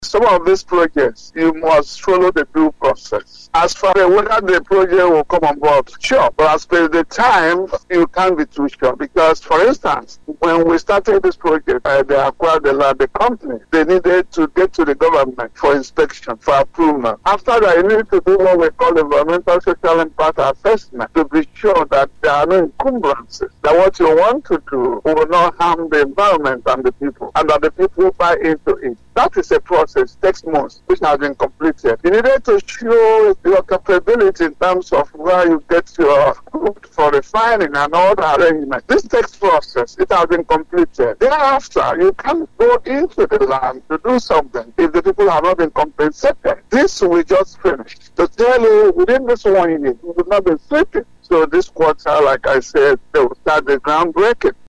Speaking on Family Love FM’s Open Parliament, monitored by dailytrailnews, the Commissioner for Petroleum and Solid Minerals Prof. Joel Ogbonna maintained that the project is on course and is being pursued with all seriousness and commitment by parties concerned.